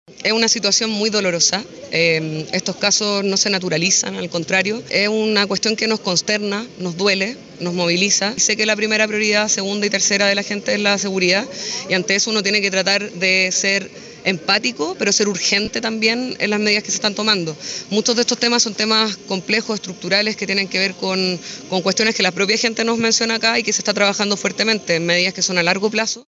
En tanto, la delegada presidencial Constanza Martínez -quien fue la primera en referirse a este lamentable hecho- mencionó que el país está de luto frente a esta lamentable situación y que se espera encuentre a los responsables: